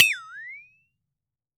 FLEXATONE  3.WAV